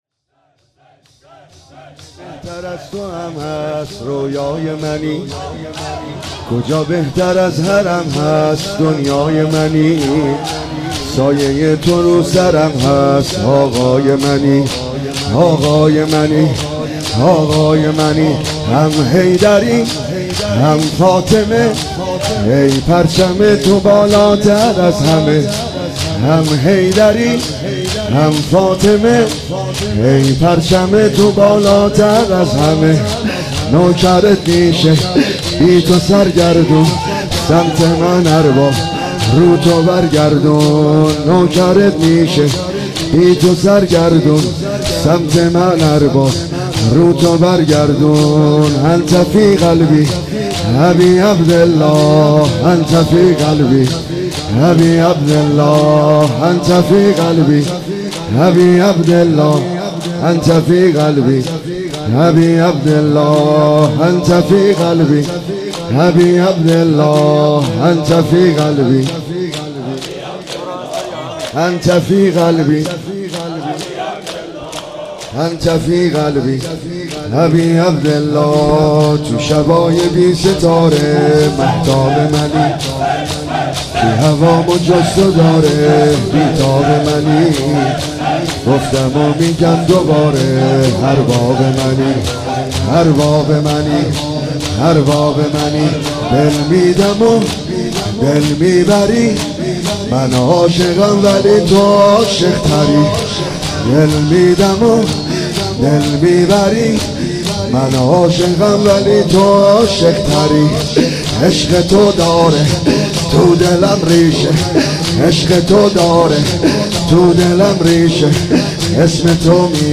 عنوان شب شهادت امام رضا علیه السلام ۱۳۹۸
شور